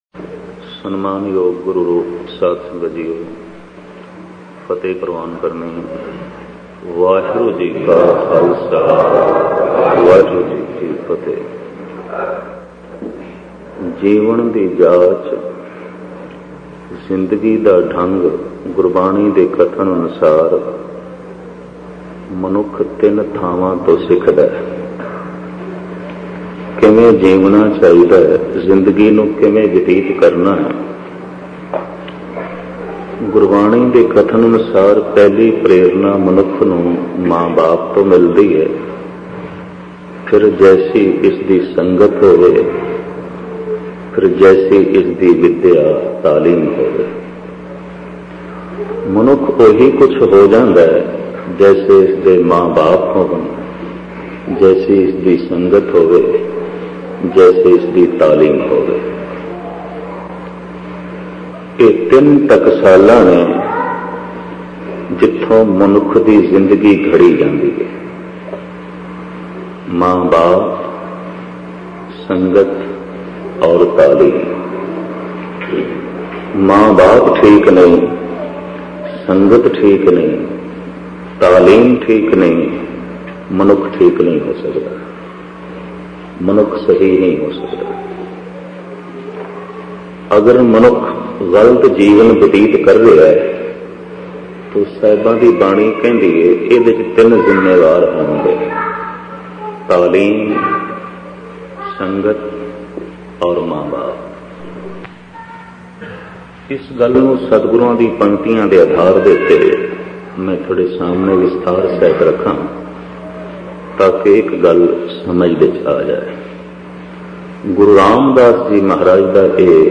Jin Har Hirdey Nam na Vasio Genre: Gurmat Vichar